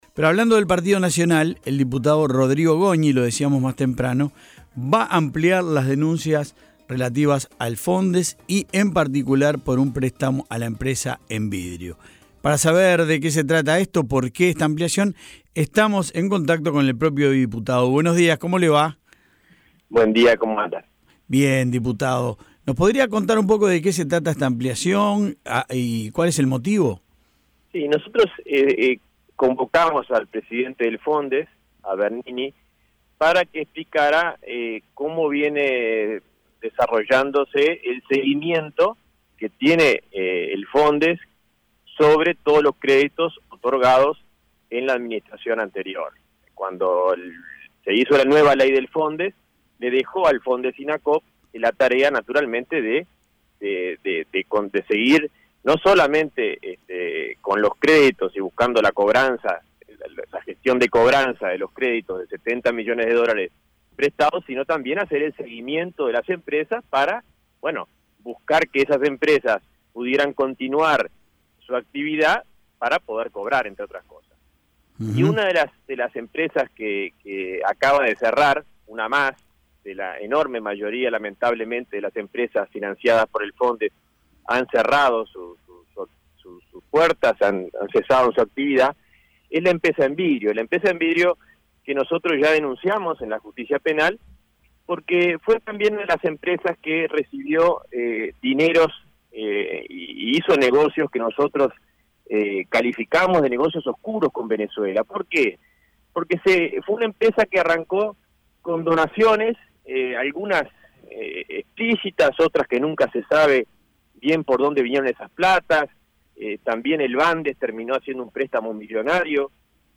El diputado nacionalista Rodrigo Goñi aseguró que Venezuela tuvo una participación "muy oscura" en el suministro de dineros al FONDES y en los préstamos a empresas como Envidrio, según dijo hoy a La Mañana de El Espectador.